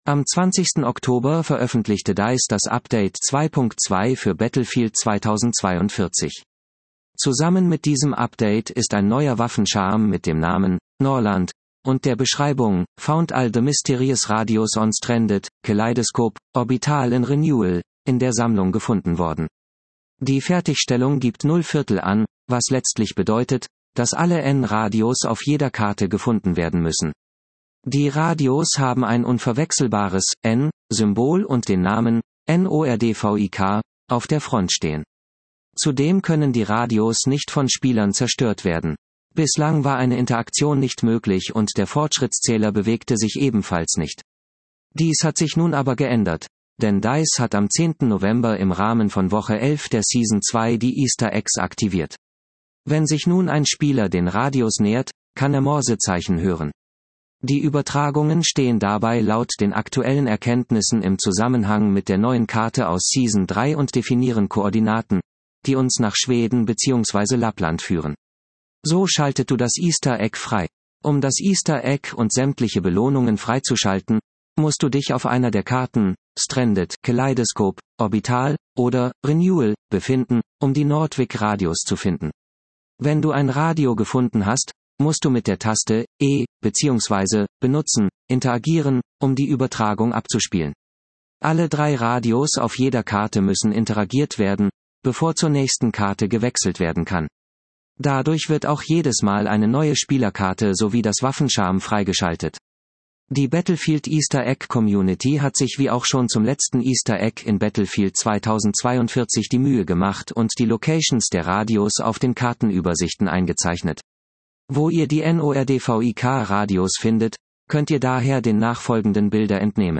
Wenn sich nun ein Spieler den Radios nähert, kann er Morsezeichen hören.